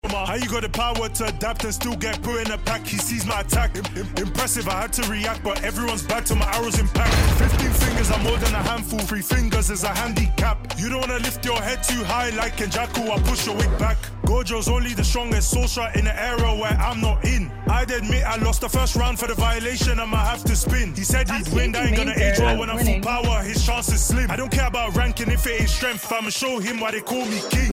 drill rap